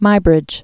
(mībrĭj), Eadweard Originally Edward James Muggeridge. 1830-1904.